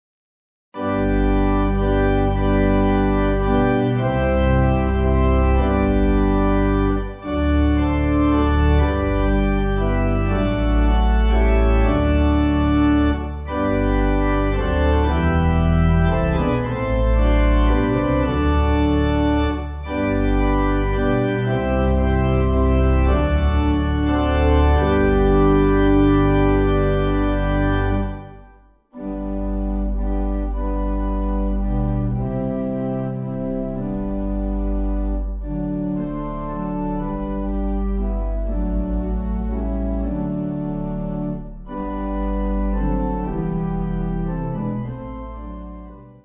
(CM)   5/G